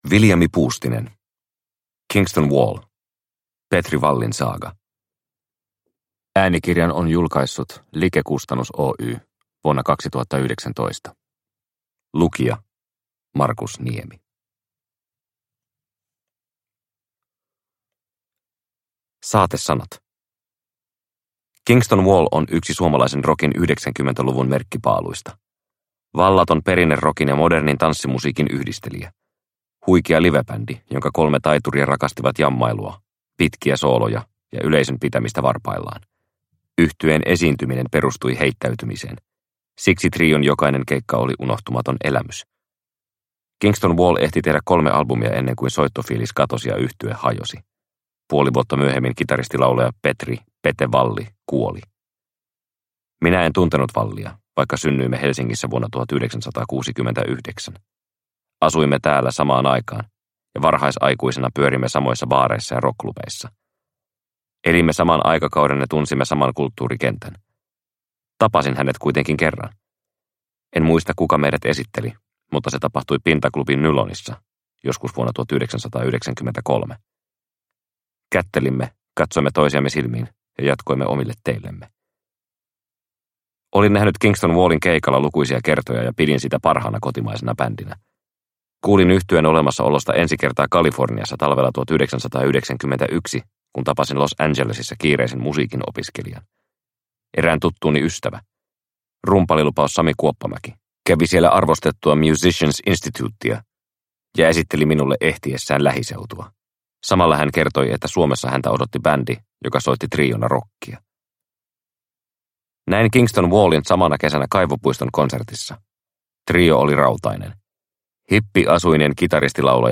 Kingston Wall – Ljudbok – Laddas ner